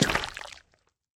Minecraft Version Minecraft Version snapshot Latest Release | Latest Snapshot snapshot / assets / minecraft / sounds / block / honeyblock / step1.ogg Compare With Compare With Latest Release | Latest Snapshot